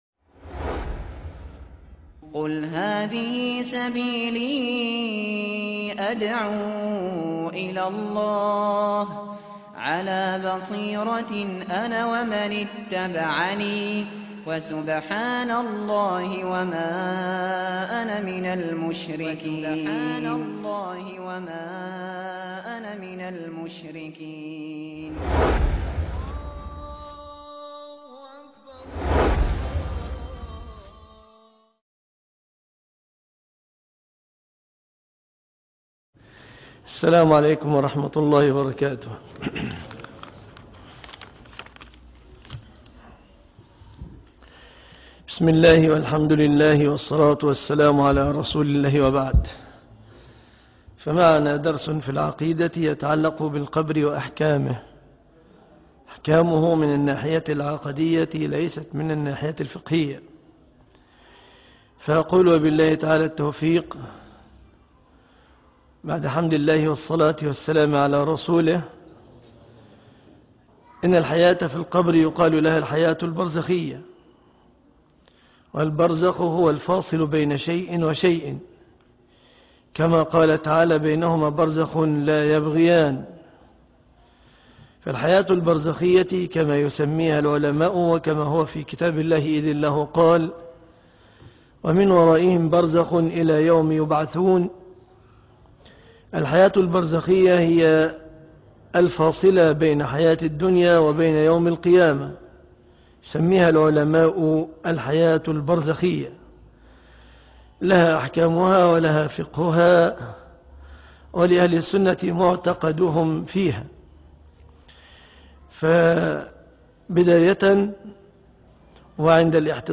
دروس العقيدة - مجمع التوحيد بالمنصورة